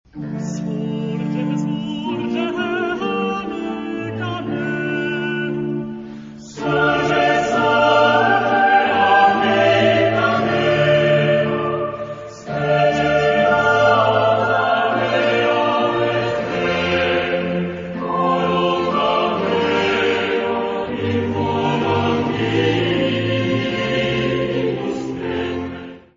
Género/Estilo/Forma: Cantata ; Sagrado
Carácter de la pieza : piadoso ; ferviente
Tipo de formación coral: SSATB  (5 voces Coro mixto )
Solistas : SSATB  (5 solista(s) )
Instrumentos: Bajo Continuo ; Violín (2) ; Violone
Tonalidad : re menor